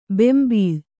♪ bembīḷ